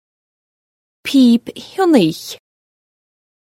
Amazon AWS (pronunciation).